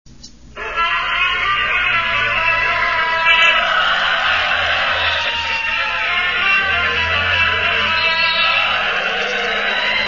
Chant nº2 des supporters 40 ko
supporters2.mp3